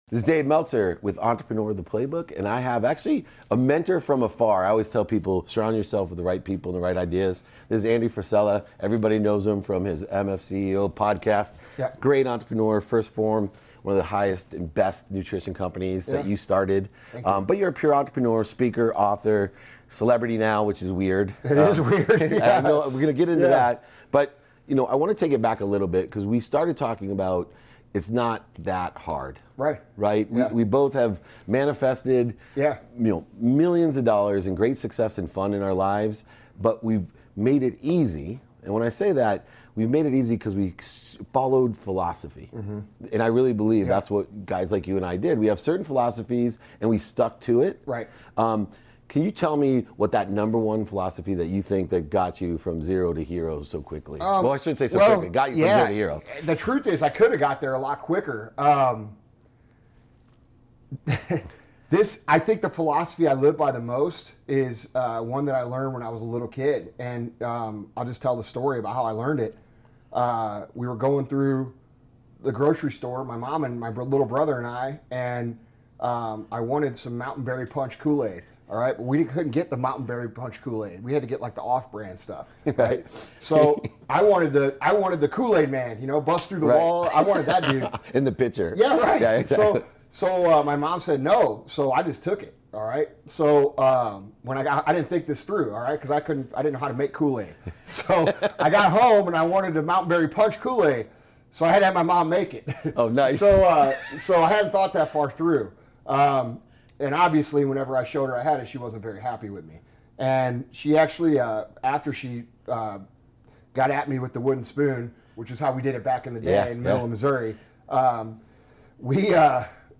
Today's episode is a throwback to a 2019 conversation I had with Andy Frisella, CEO of 1st Phorm and host of The RealAF Podcast.